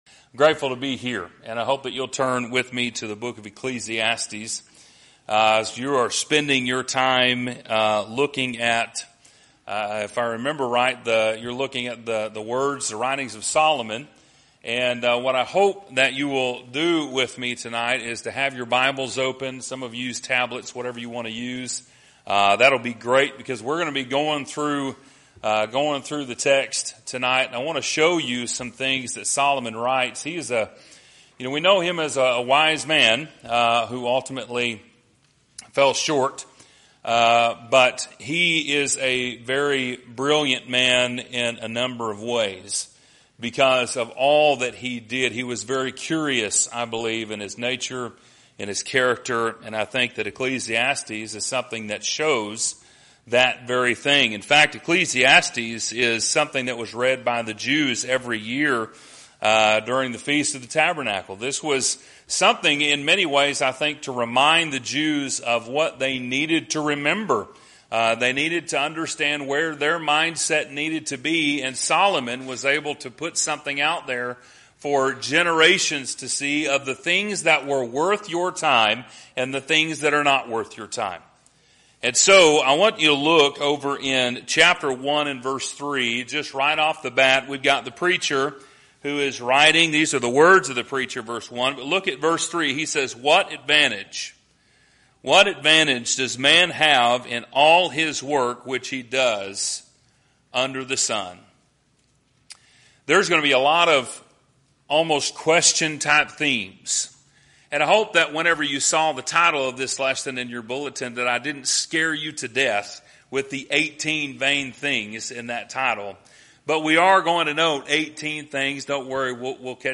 A Faithful New Testament Church whose mission is to share the love and gospel of Jesus Christ with our community and the world.